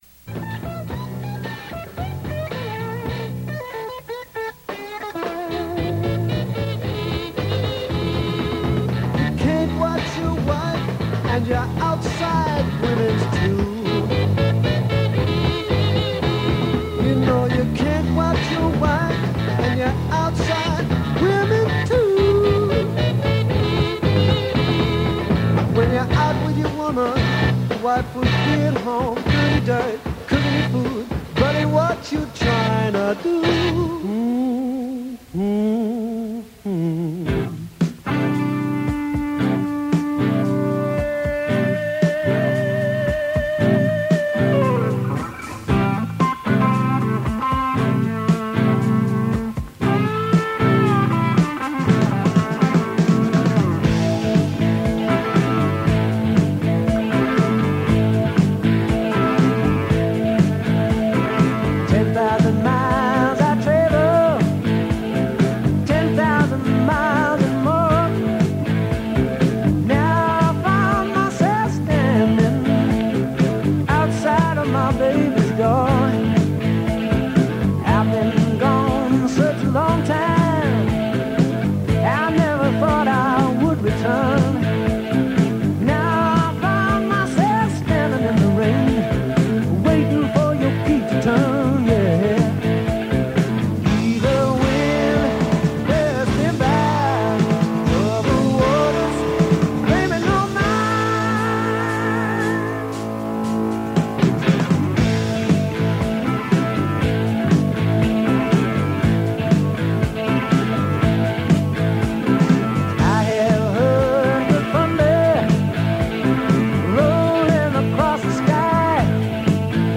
KMET is still hanging on to Hard Rock, still spending a lot of time in the 60s.